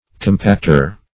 Compacter \Com*pact"er\